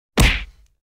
Punch Sound Effect Download: Instant Soundboard Button
Punch Sound Button - Free Download & Play